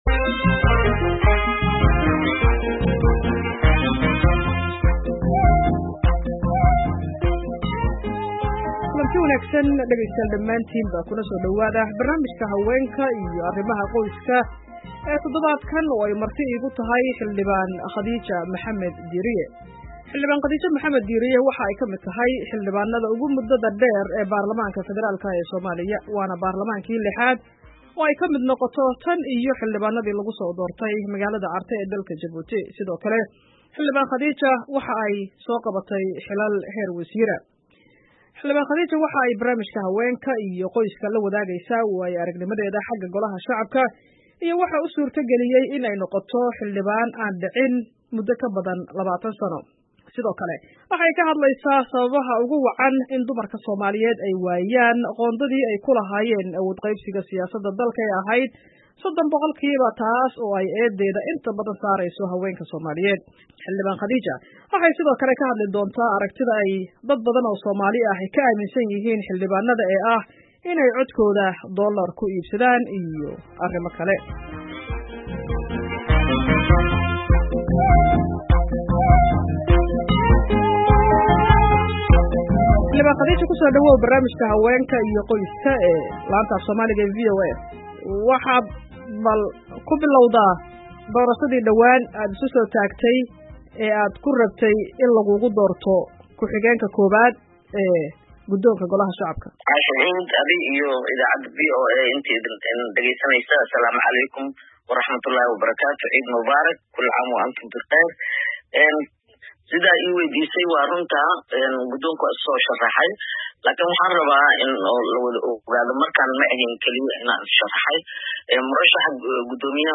Barnaamijka Haweenka iyo Arrimaha Qoyska: Wareysiga Khadiija Maxamed Diiriye
Barnaamijka Haweenka iyo Qoyska ee toddobaadkan waxaa marti ku ah Xildhibaan Khadiija Maxamed Diiriye oo ka mid ah xildibaanda ugu muddada dheer ee baarlamaanka Federaalka ah ee Soomaaliya Khadiija waxaa dhowaan markii lixaad loo doortay xildhibaan.